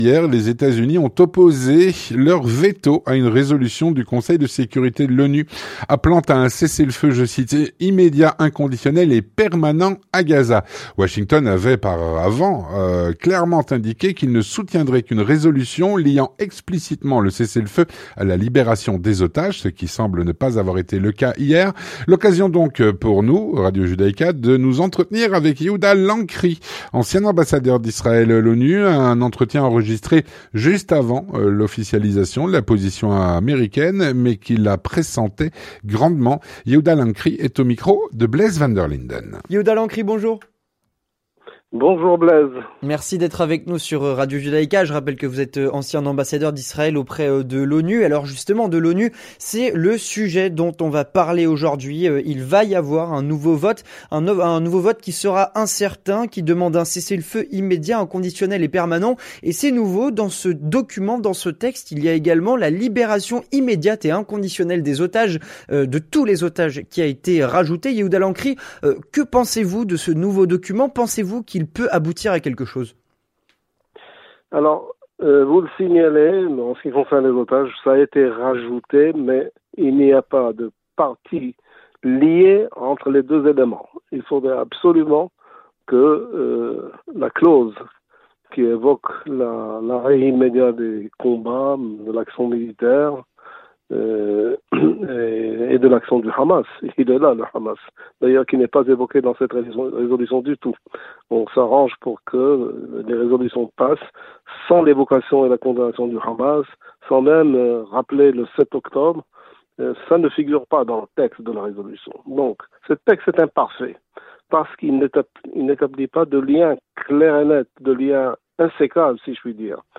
Avec Yehuda Lancry, ancien ambassadeur d'Israël à l’ONU. Un entretien enregistré juste avant l'officialisation de la position américaine mais qui la pressentait grandement.